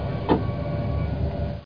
SQUELCH.mp3